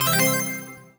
collect_item_jingle_02.wav